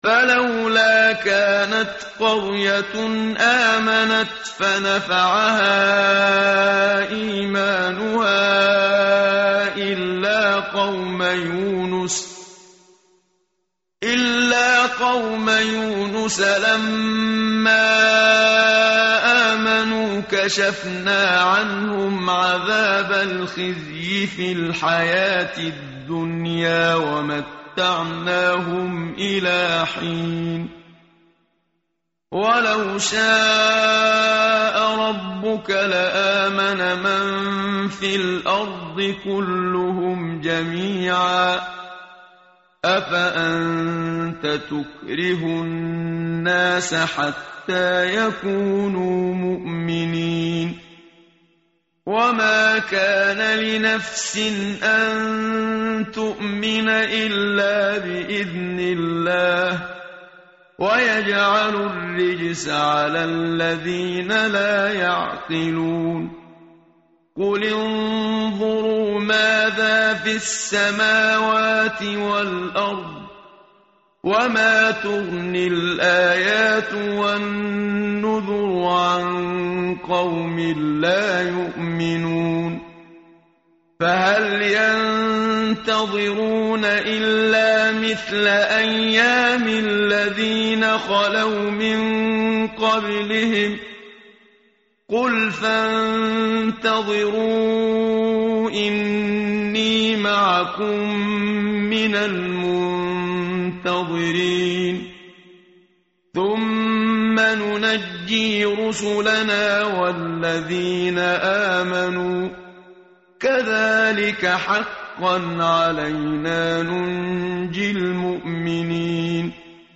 متن قرآن همراه باتلاوت قرآن و ترجمه
tartil_menshavi_page_220.mp3